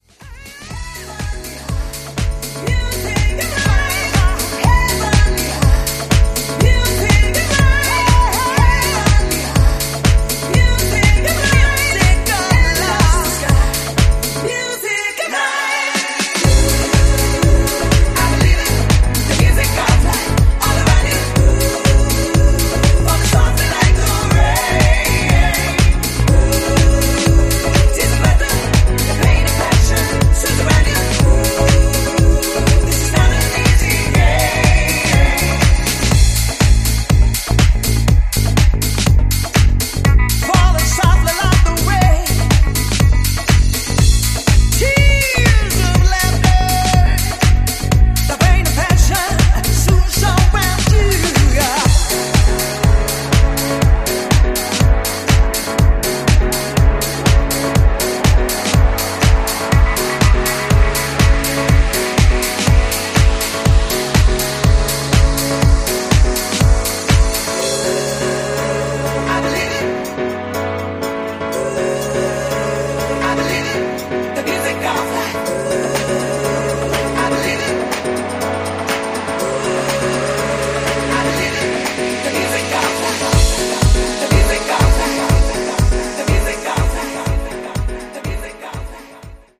This package delivers two quality soulful house cuts
slick remix